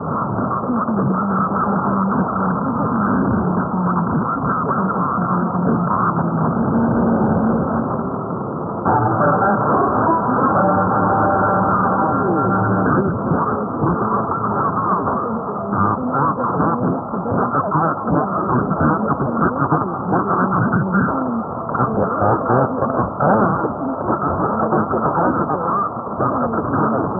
Here's a short sample of what I had on 621 - across TOH ... splatter courtesy of KPOJ:
Conditions were not great but nor was the weather or my mauling of a new antenna design I was trying.